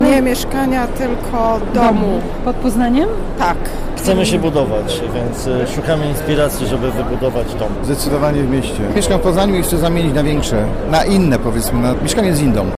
Rozmawiała z nimi reporterka Radia Poznań: